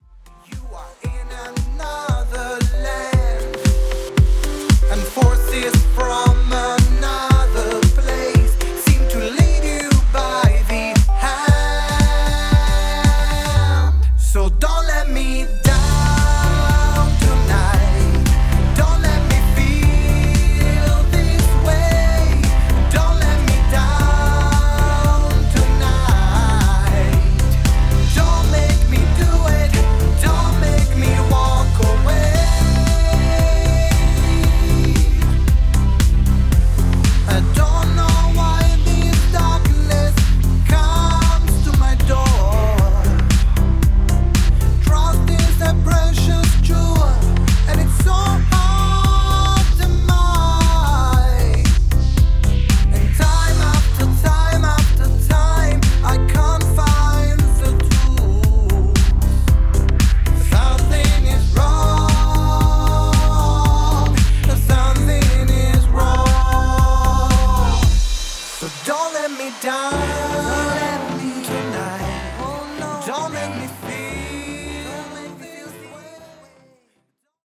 DANCE POP